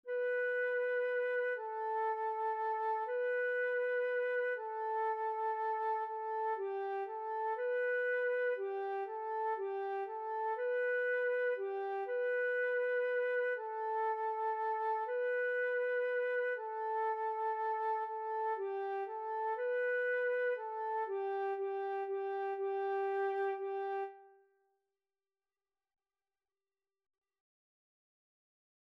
3/4 (View more 3/4 Music)
G5-B5
Flute  (View more Beginners Flute Music)
Classical (View more Classical Flute Music)